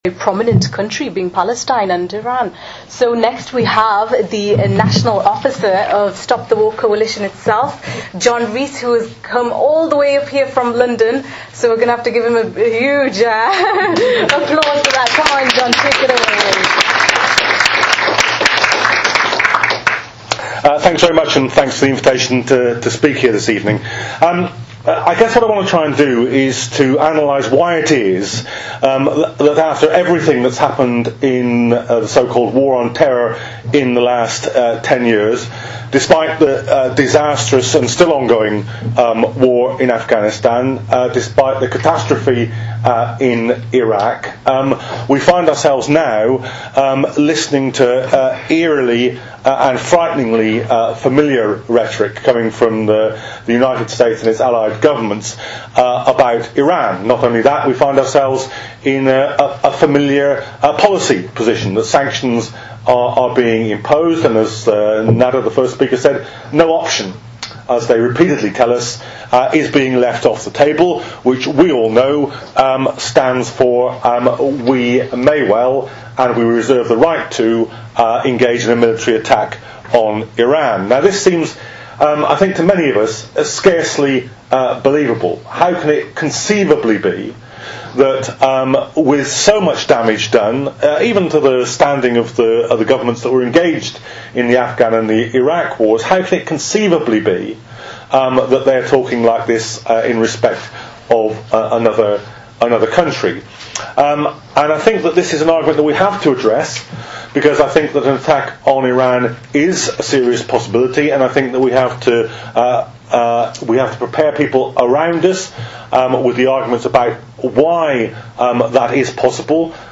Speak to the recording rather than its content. Don't Attack Iran - Stop the War public meeting in Newcastle A public meeting took place in Northumbria University on Thursday, 16 February 2012 to oppose the British government's criminal warmongering stance towards Iran.